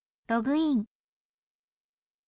ออกเสียง: ro, โระ
rogu in, โระกึ อิง